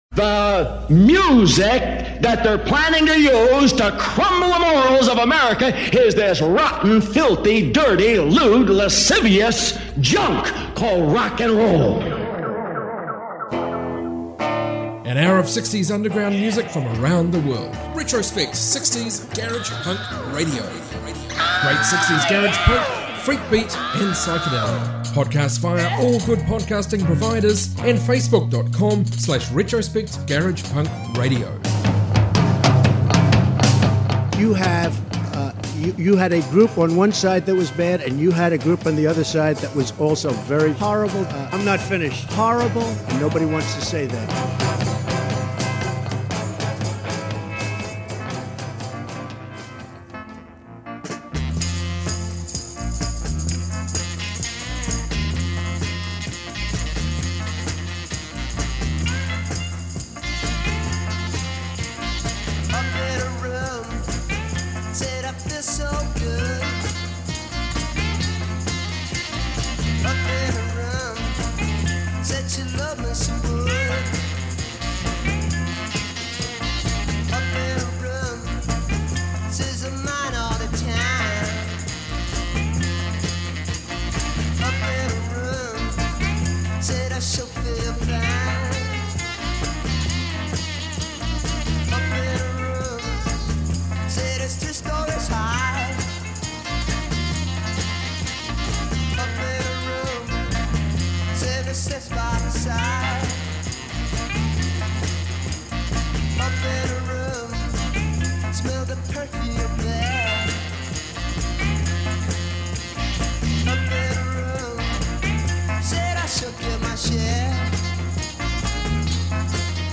60s garage punk, garage rock, freakbeat from around the globe